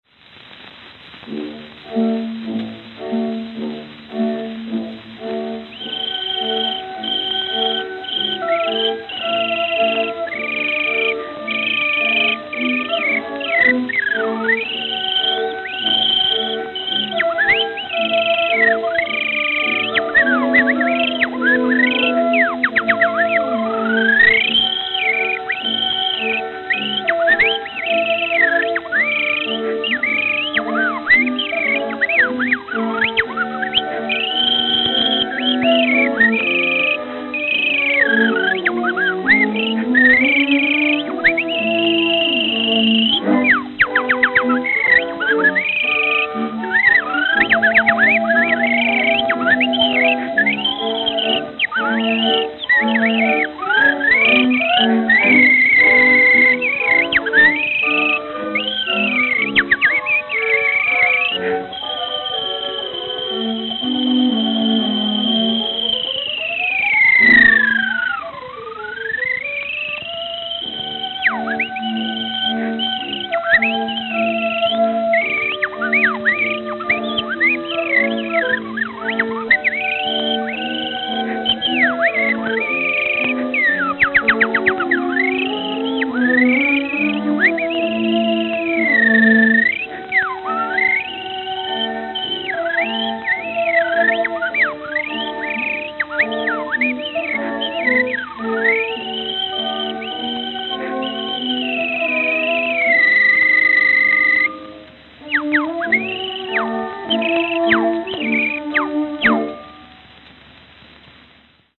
Note: Several hairline cracks from center, not audible.